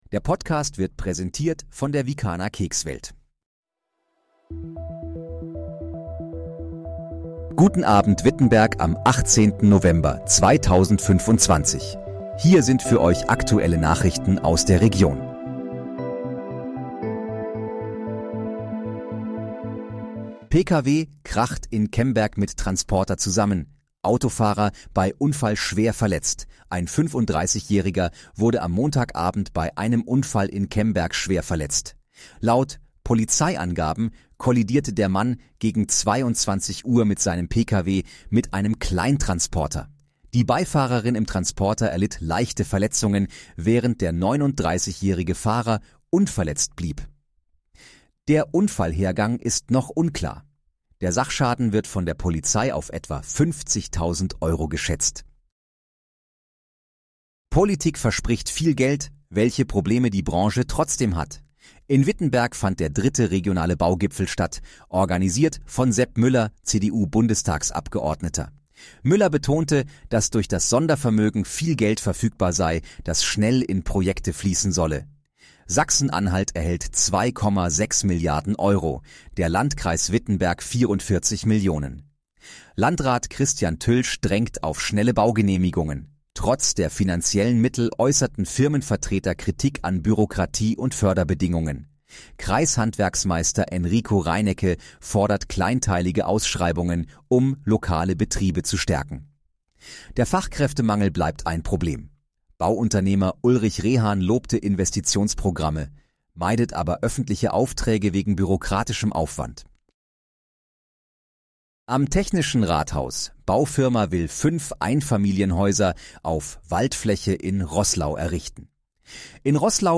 Guten Abend, Wittenberg: Aktuelle Nachrichten vom 18.11.2025, erstellt mit KI-Unterstützung
Nachrichten